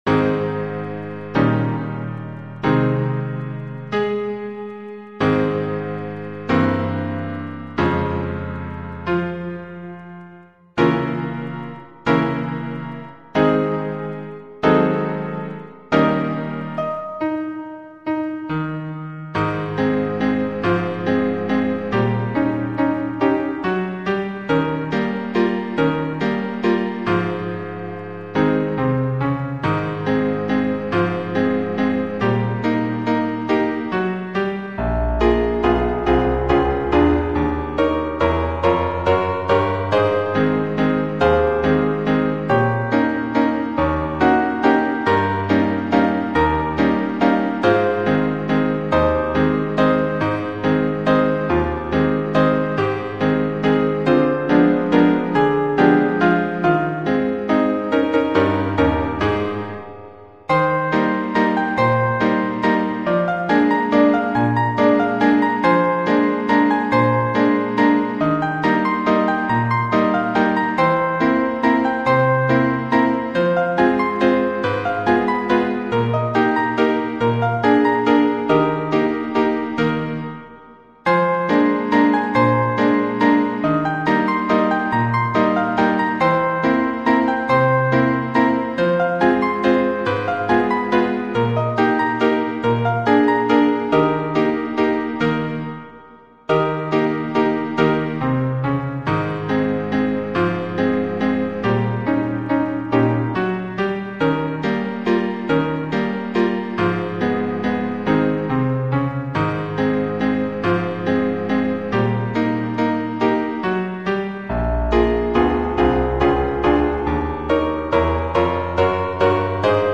We selected instruments that sounded like upright Victorian pianos; in many cases, the lyrics line is played back as a honky-tonk piano, while the supporting bass and treble lines were kept to more sedate parlor pianos. The reverb is what we imagine these tunes would have sounded like had then been played in the Hotel Florence (with its Minton tile lobby) in 1885.